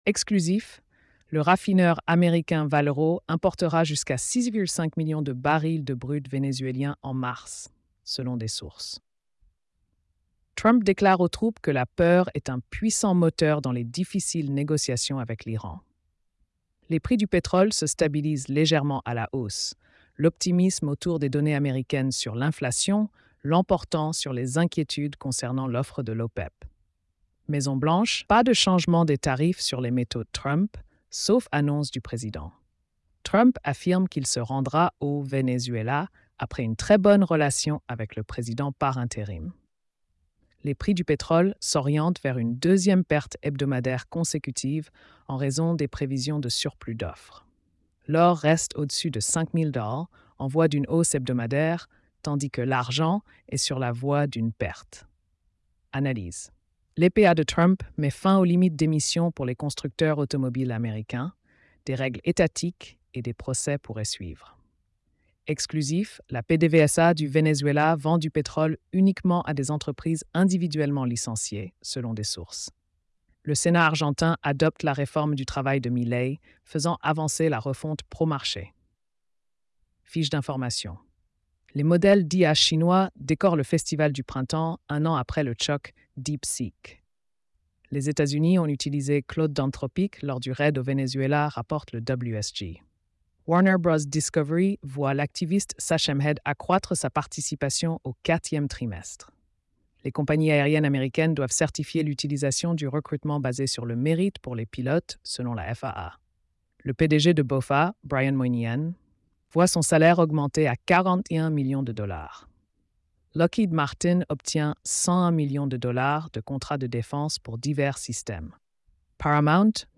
🎧 Résumé des nouvelles quotidiennes. |